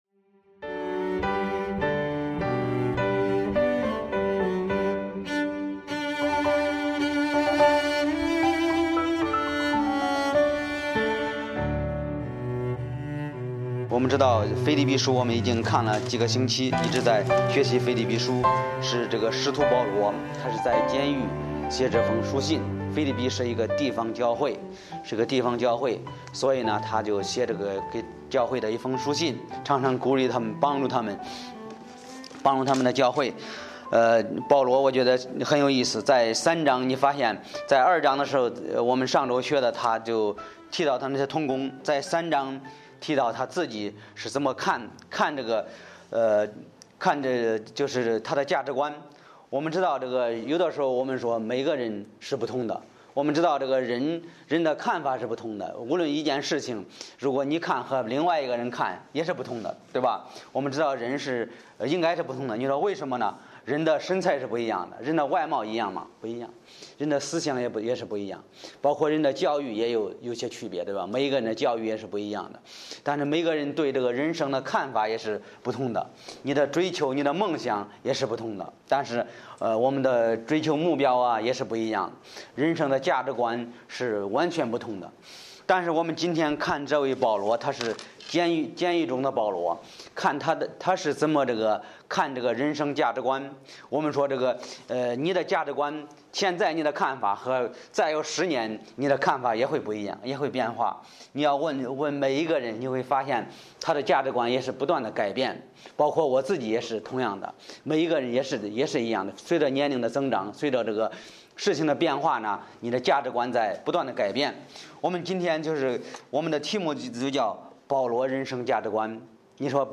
Bible Text: 腓立比书3：1-11 | 讲道者